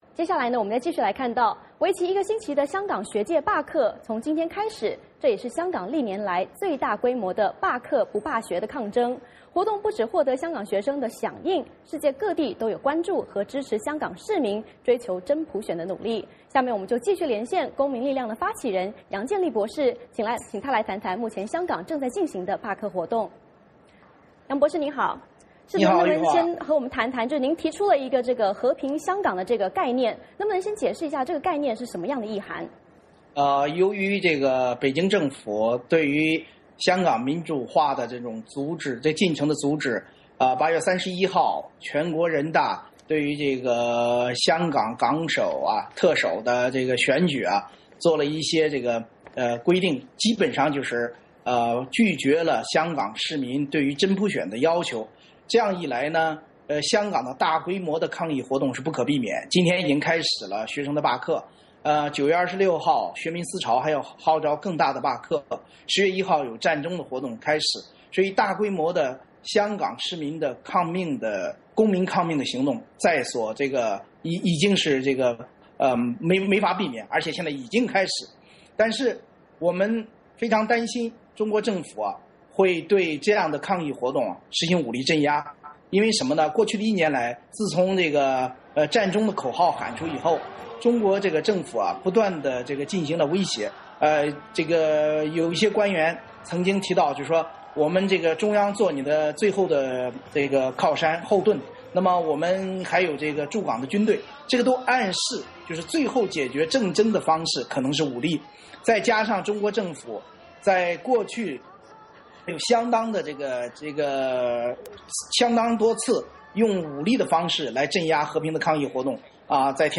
我们连线了香港公民力量的发起人杨建利博士，请他为我们介绍相关的情况。